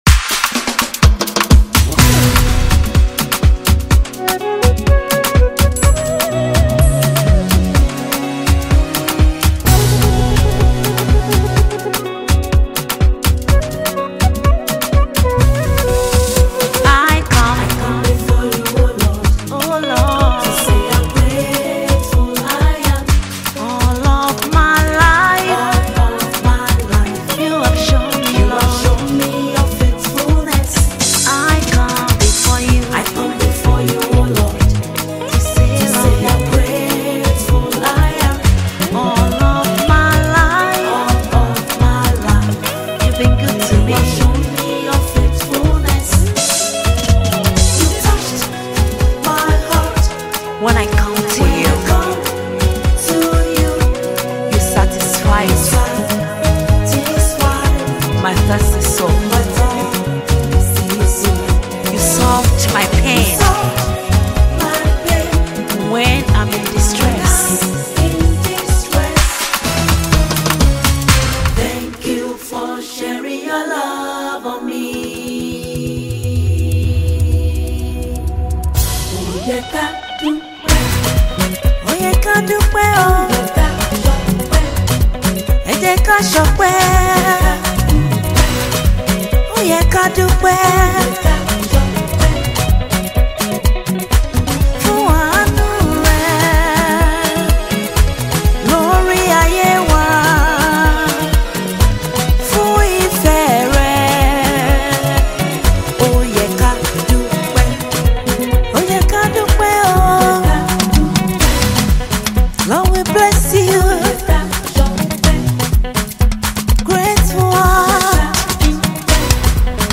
Versatile Nigerian gospel music minister